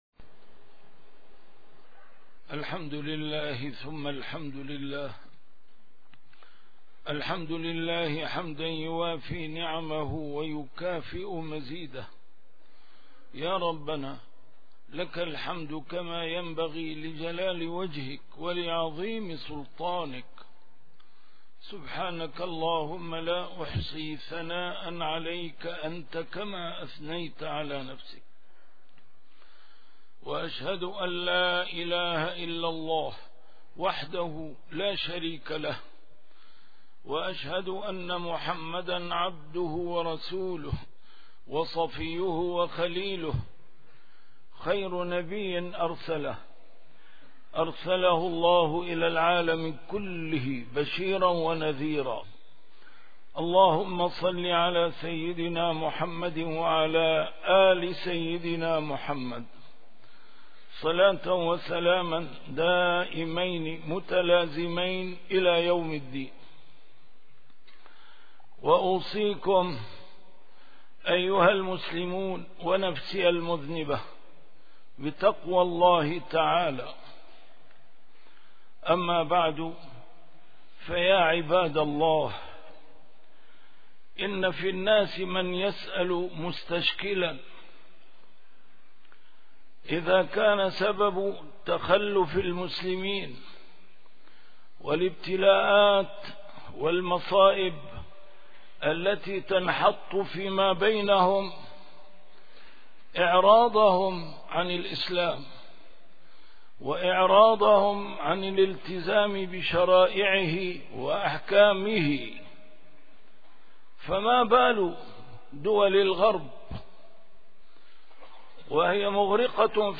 A MARTYR SCHOLAR: IMAM MUHAMMAD SAEED RAMADAN AL-BOUTI - الخطب - سبب التخلف والتقدم